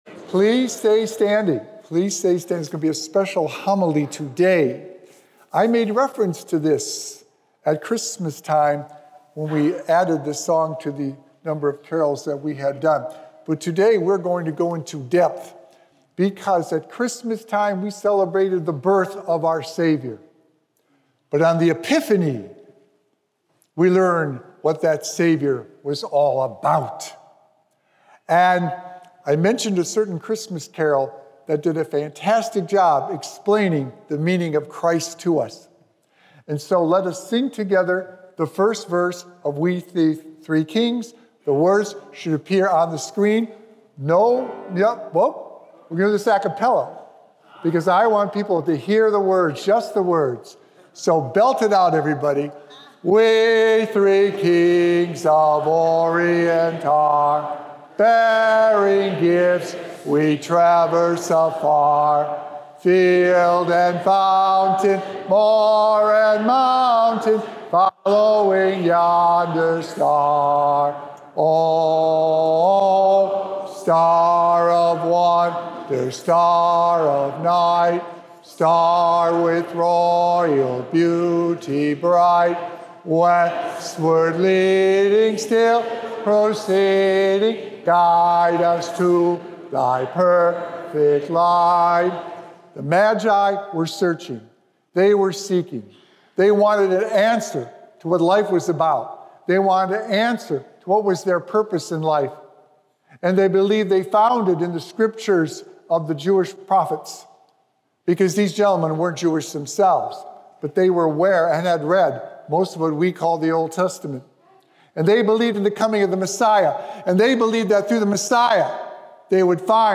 Sacred Echoes - Weekly Homilies Revealed